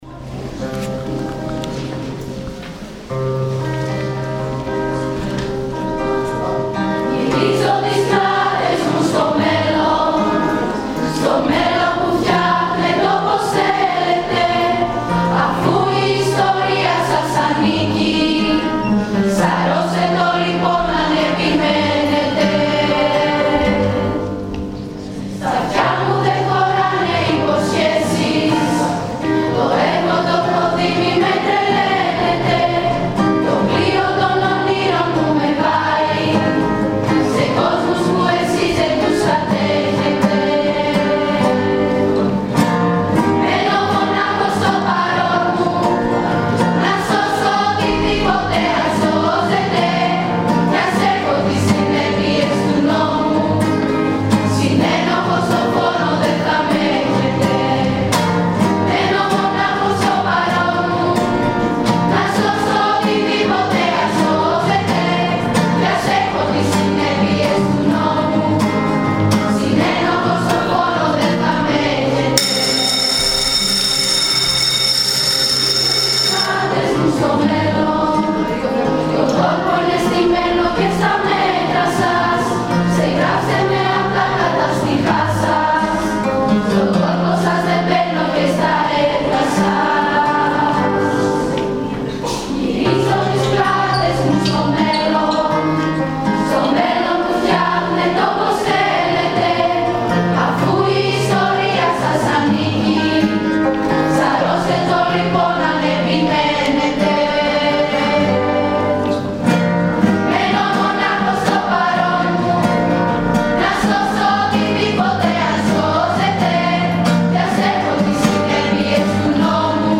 Μια καταπληκτική γιορτή μνήμης για τη 17 Νοέμβρη διοργάνωσαν οι μαθητές του σχολείου μας μαζί με τους καθηγητές τους. Πάρτε μια γεύση ακούγοντας την χορωδία.